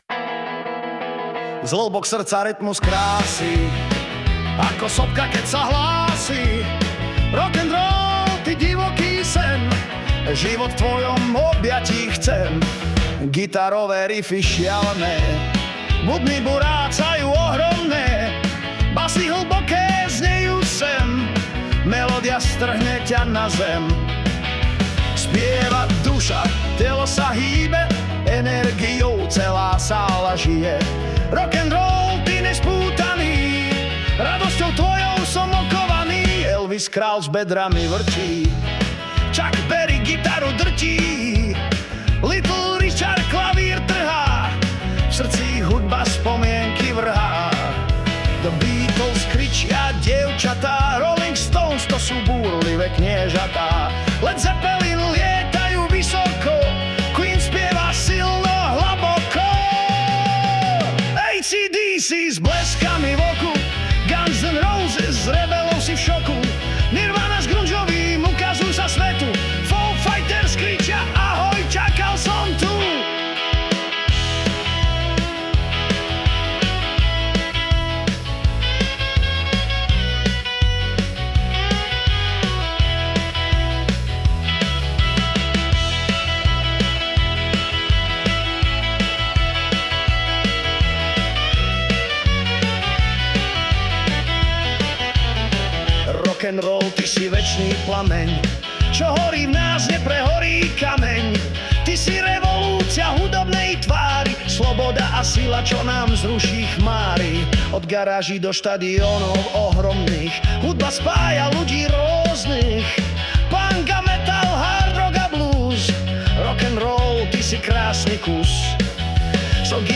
Poslechla jsem si s chutí a musím říct, v té music podobě to má ty správný rock and roll grády... tady ten text je šitej tý hudbě prostě na míru a povedlo se ti skvěle:-) Ještě si zajdu a hodím si minci do Iron-juboxu:-))*
Ale dílo má to švunk a prima rytmus.